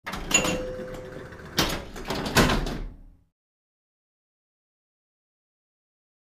Pinball Machine; New Game Ball Thump, Close Perspective